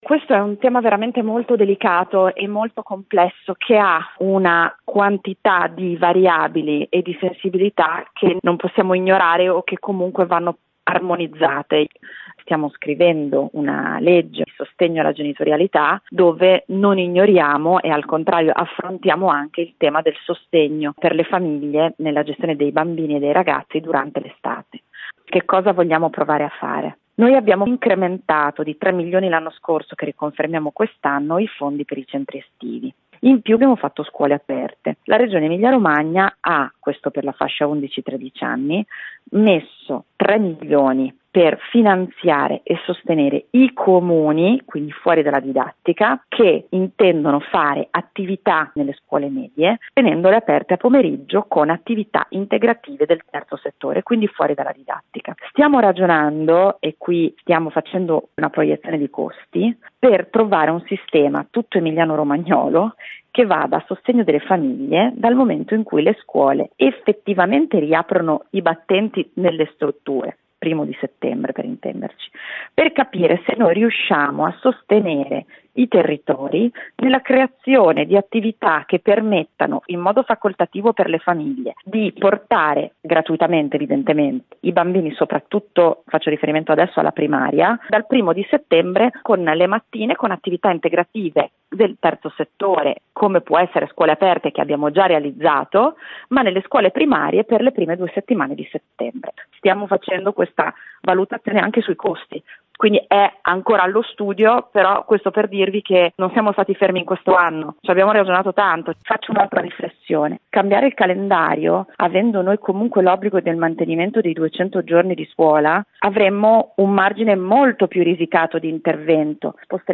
Isabella Conti, assessore all’istruzione della Regione ER